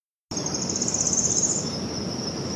Pitiayumí (Setophaga pitiayumi)
Nombre en inglés: Tropical Parula
Fase de la vida: Adulto
Localidad o área protegida: Reserva Ecológica Costanera Sur (RECS)
Condición: Silvestre
Certeza: Vocalización Grabada